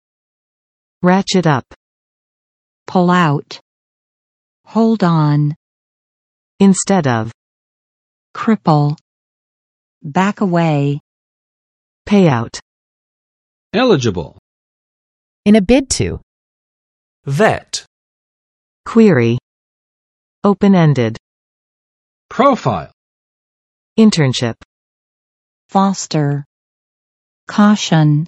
[ˋrætʃɪt] up 冲高；逐渐升高，步步提升
ratchet up.mp3